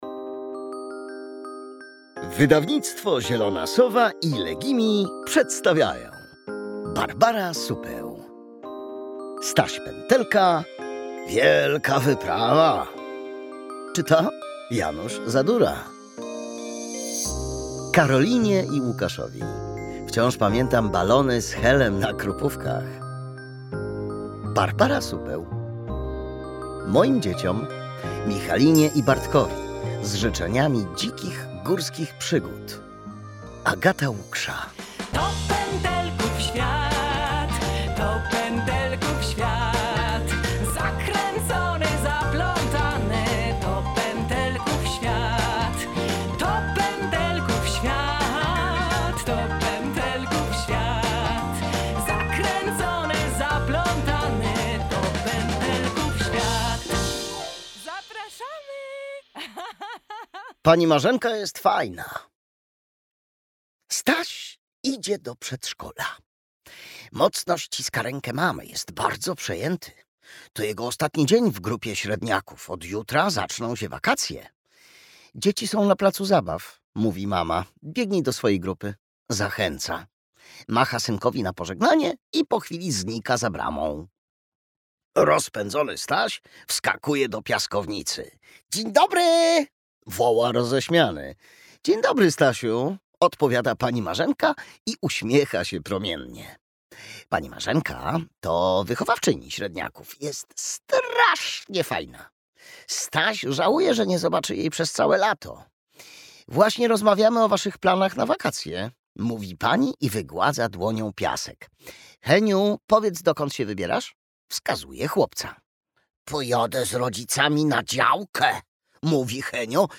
Wielka wyprawa - Barbara Supeł - audiobook + książka